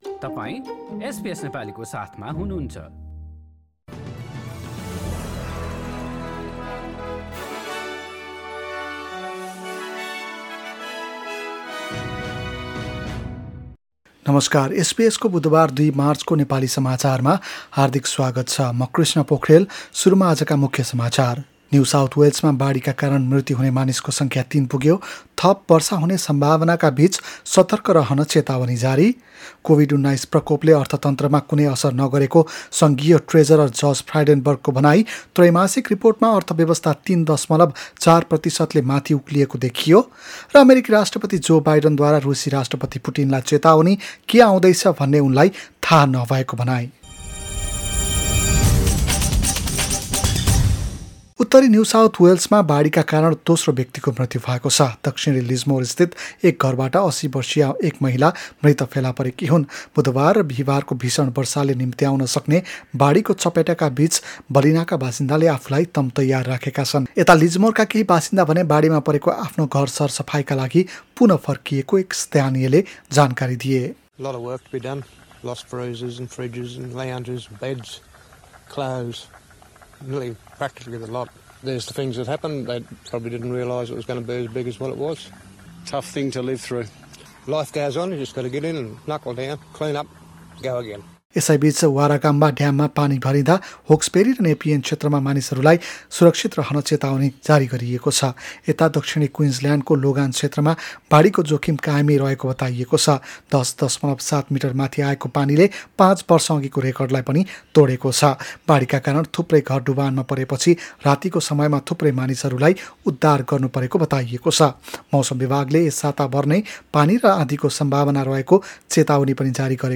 एसबीएस नेपाली अस्ट्रेलिया समाचार: बुधवार २ मार्च २०२२